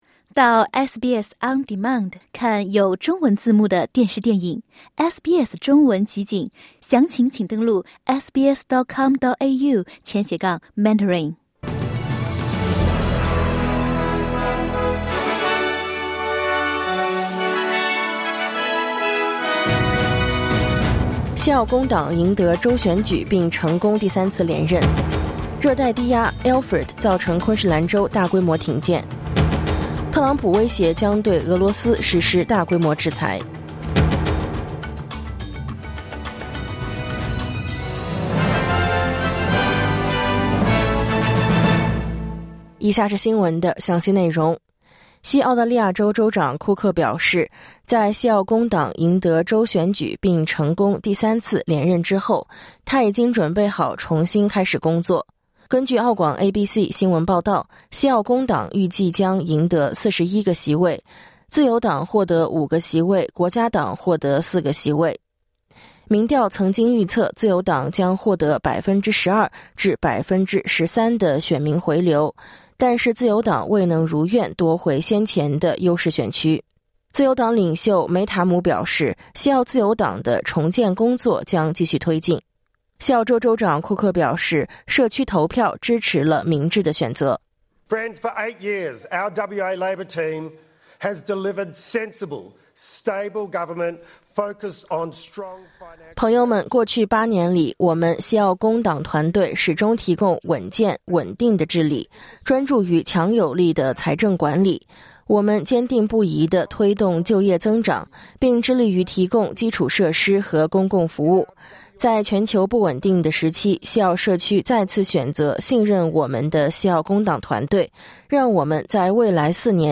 SBS早新闻（2025年3月9日）